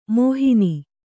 ♪ mōhini